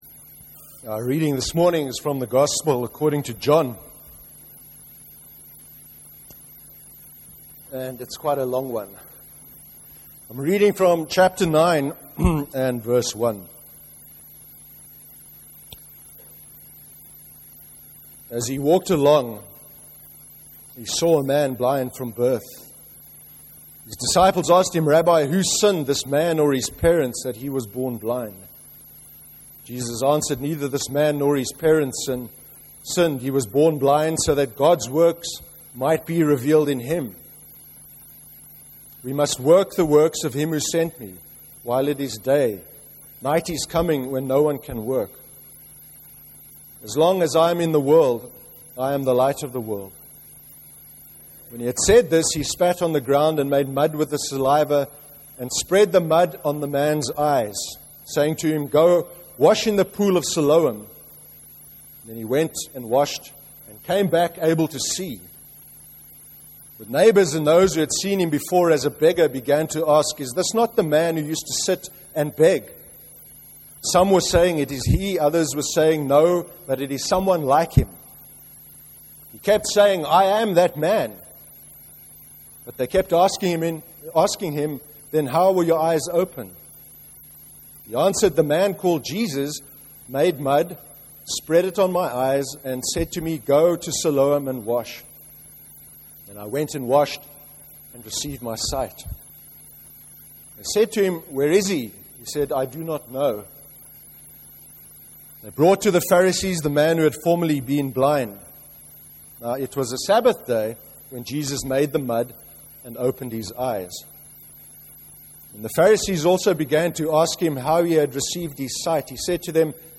10/02/13 sermon – Our identity as Christians, and Christian Faith (John 9:1-38)